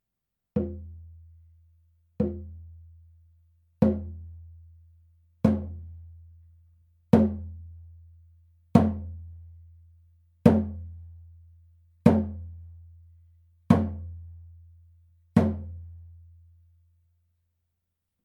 ネイティブ アメリカン（インディアン）ドラム NATIVE AMERICAN (INDIAN) DRUM 16インチ（buffalo バッファロー）
ネイティブアメリカン インディアン ドラムの音を聴く
乾いた張り気味の音です 温度・湿度により皮の張り（音程）が大きく変化します